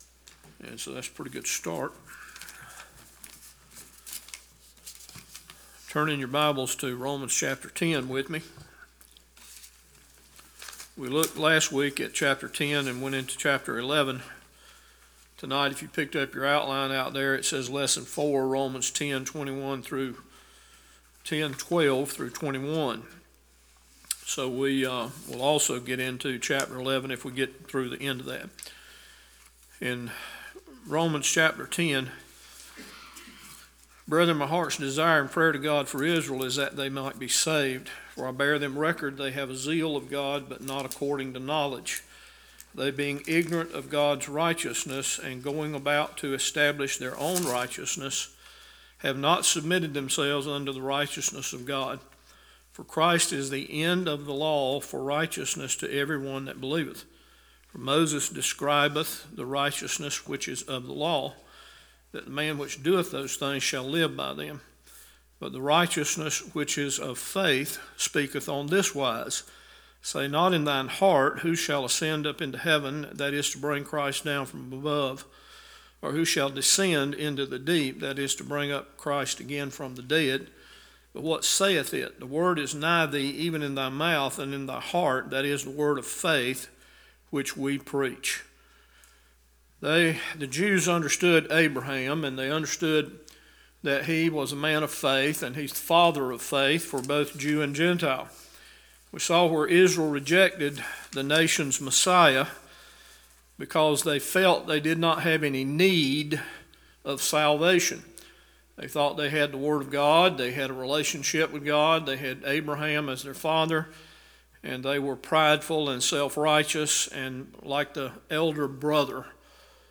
Romans Bible Study 17 – Bible Baptist Church